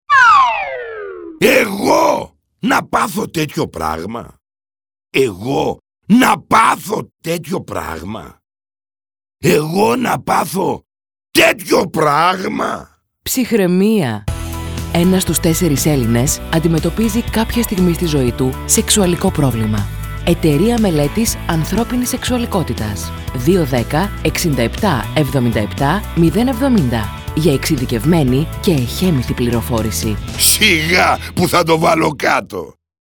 ραδιοφωνικό σποτ δίνει η ερμηνεία του ηθοποιού Γιάννη Μποσταντζόγλου ο οποίος με τη χαρακτηριστική φωνή του στήριξε την προσπάθειά της Ε.Μ.Α.Σ. με την ευγενική συμμετοχή του.
Ραδιοφωνικό Σποτ_Κοινωνικό Μήνυμα_Ε.Μ.Α.Σ..mp3